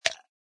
ice.mp3